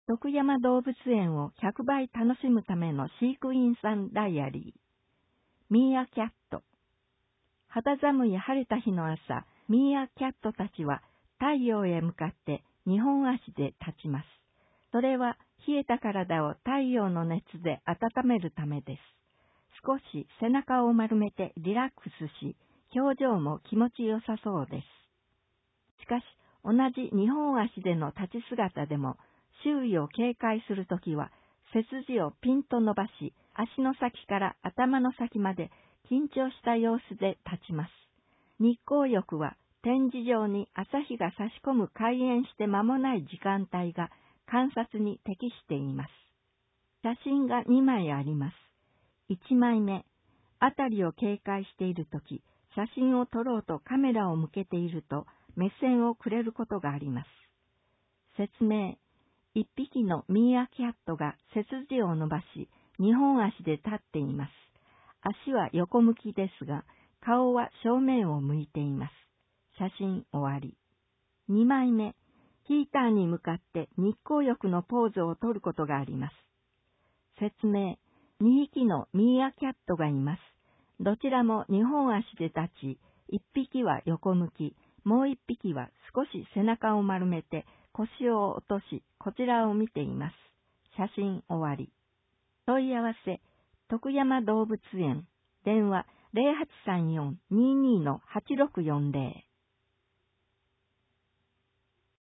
音訳広報
広報しゅうなんを、音読で収録し、mp3形式に変換して配信します。
この試みは、「音訳ボランティアグループともしび」が、視覚障害がある人のために録音している音読テープを、「周南視聴覚障害者図書館」の協力によりデジタル化しています。